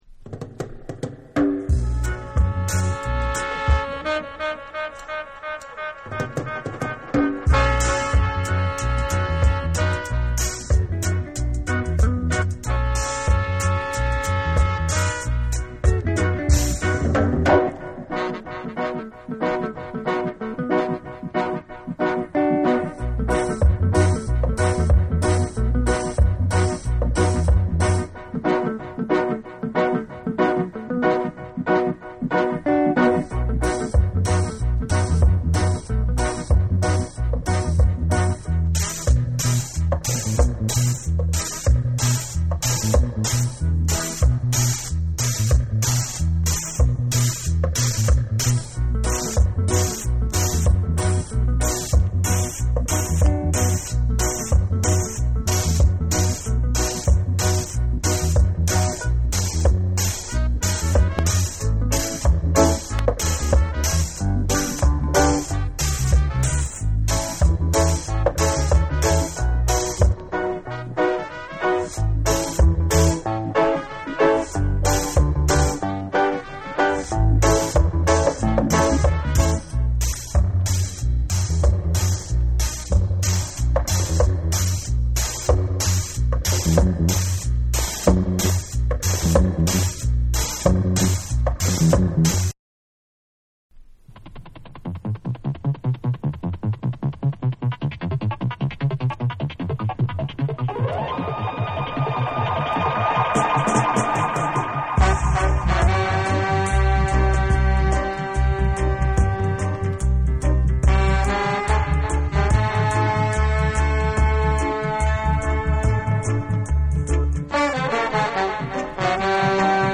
70年代中期〜後期のダンスホール・リディムをリメイクした作品
ダブ・サウンドの魅力が充分に楽しめる1枚に仕上がっています
REGGAE & DUB